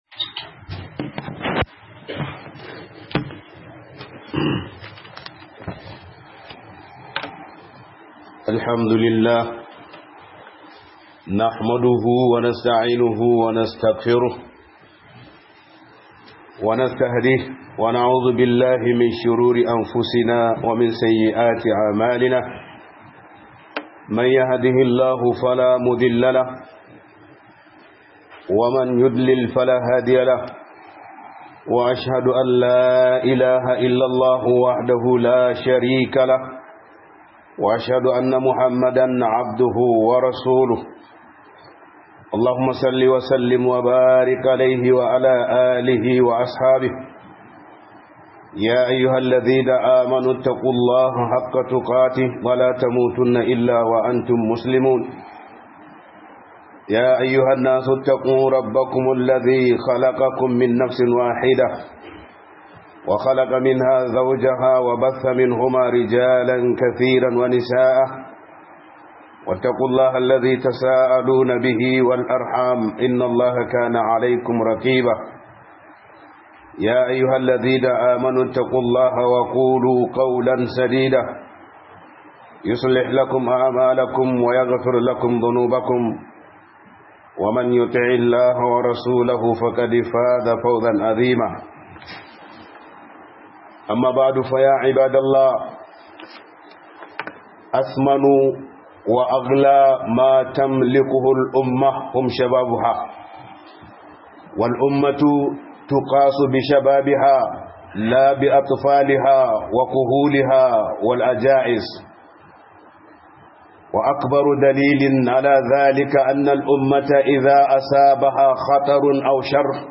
Play Radio